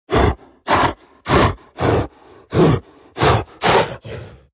Звуки гориллы
Быстрое дыхание зверя